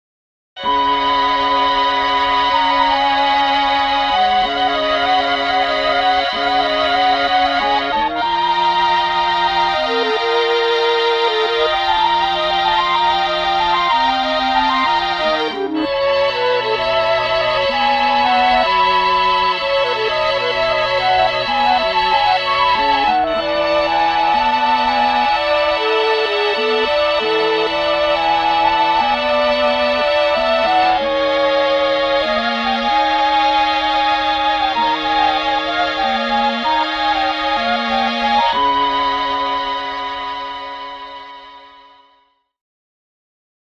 07-Mellotron MIX
07-Mellotron-MIX.mp3